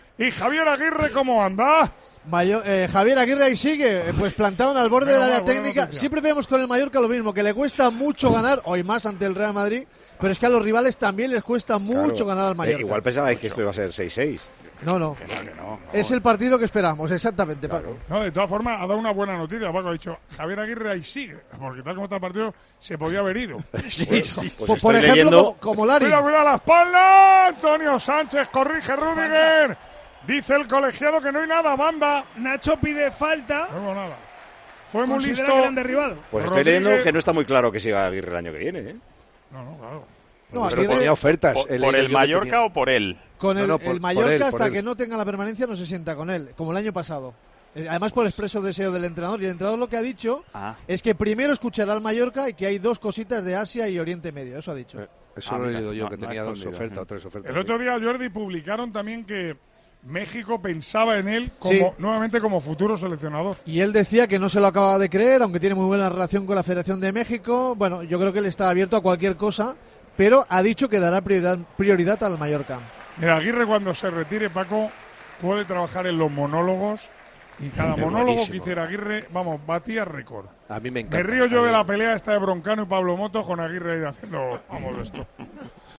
Para retransmitir el encuentro para Tiempo de Juego, acudió a la isla de Palma de Mallorca Manolo Lama y durante la disputa del mismo, dio su opinión sobre el futuro del técnico local, el mexicano Javier Aguirre, para cuando decida retirarse. Pincha en el siguiente audio para escuchar íntegro este fragmento del duelo entre el Mallorca y el Real Madrid.